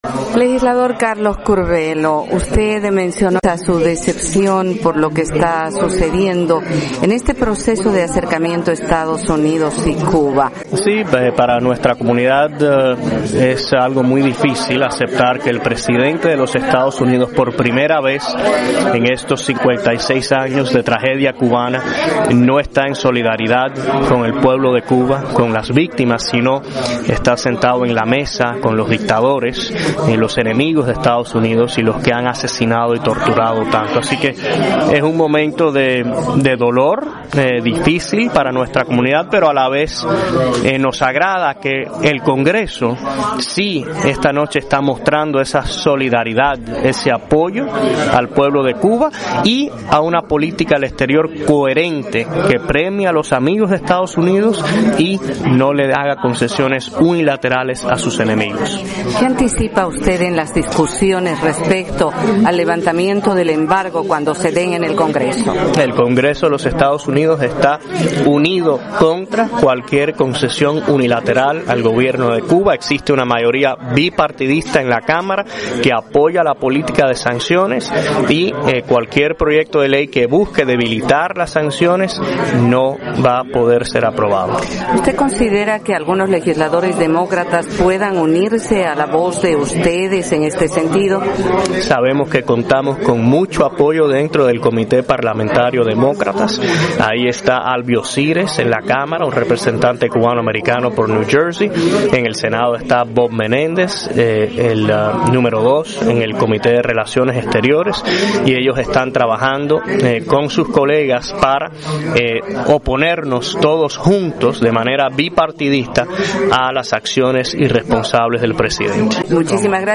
Entrevista con el representante republicano Carlos Curbelo